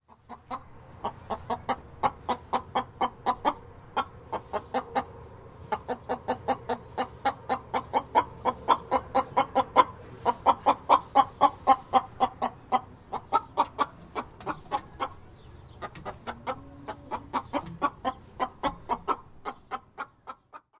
На этой странице собраны разнообразные звуки курятника: от кудахтанья кур до петушиных криков на рассвете.
Звук курицы, вышедшей из курятника на улицу